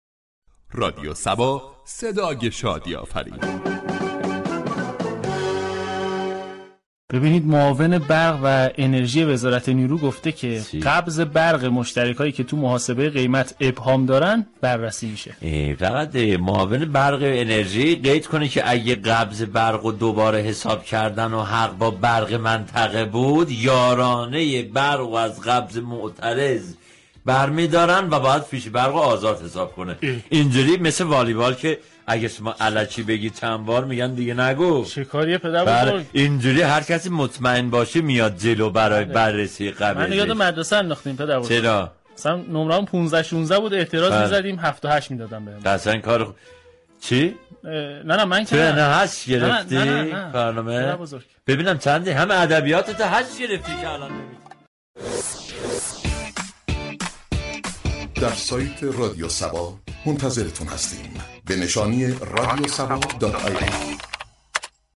صبح صبا كه هر روز درساعت 07:00 صبح با پرداختن به موضوعات و اخبار روز جامعه ،لبخند و شادی را تقدیم مخاطبان می كند در بخش خبری با بیان طنز به خبر قبض های شوكه آور برق پرداخت.